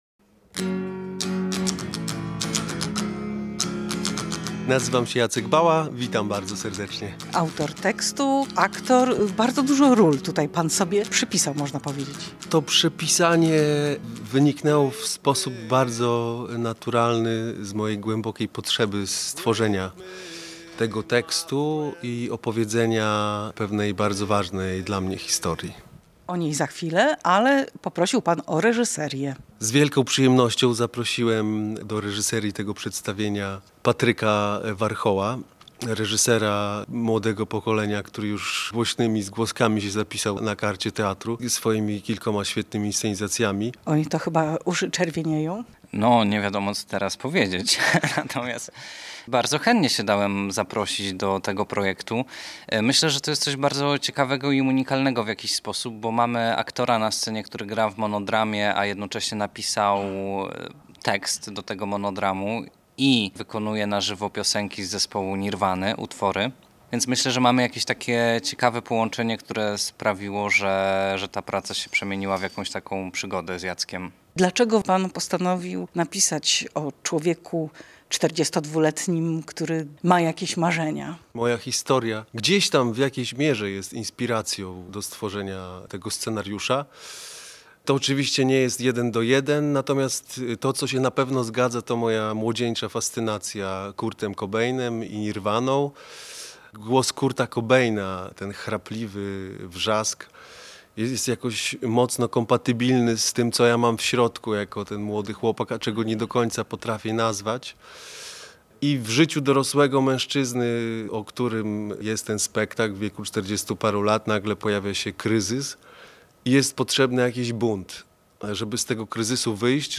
Posłuchaj rozmów z twórcami spektaklu: